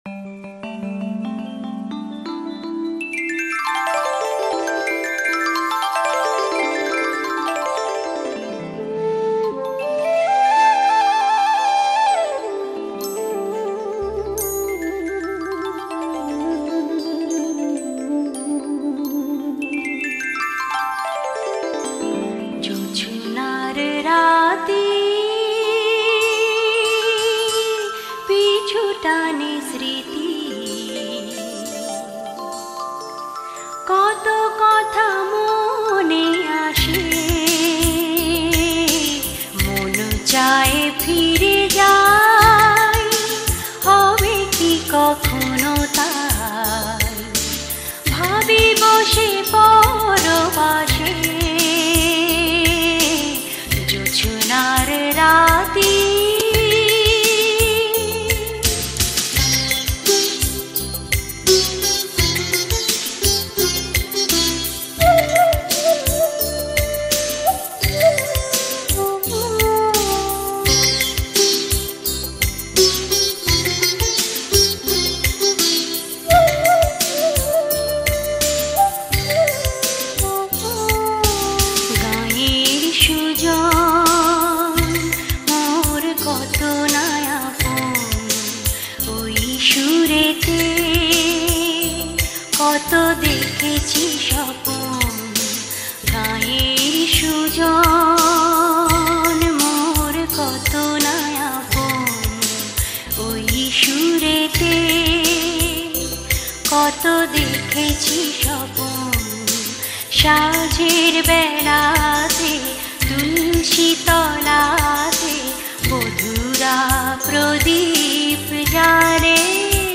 Album